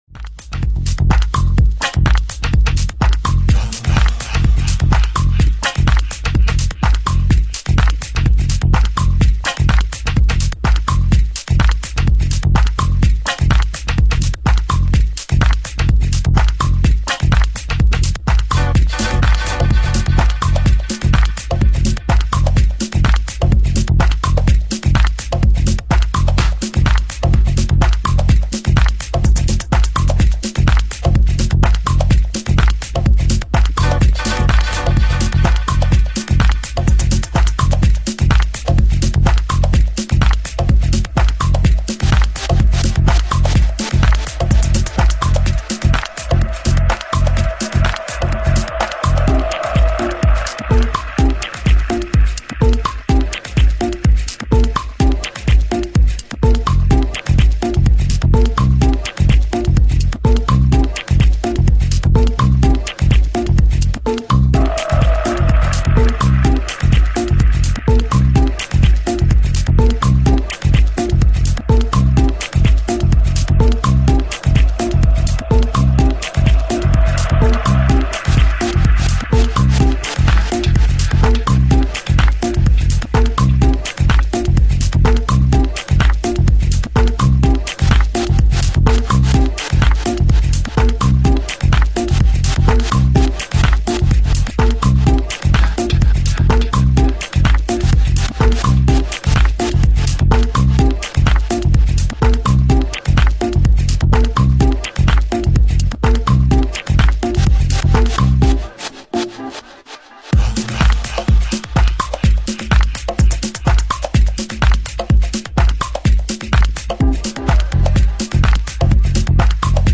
a pure blend of finest detroit techno/electro
Electro Techno Detroit